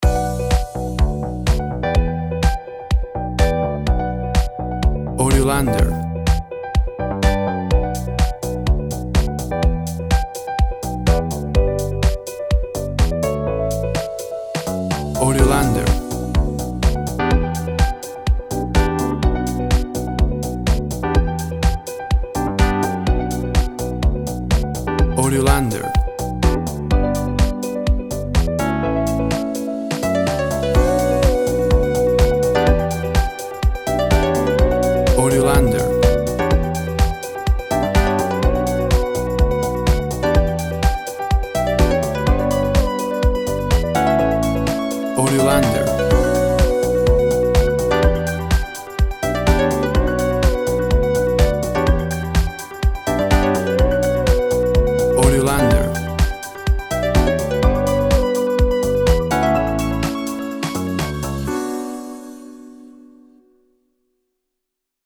WAV Sample Rate 16-Bit Stereo, 44.1 kHz
Tempo (BPM) 125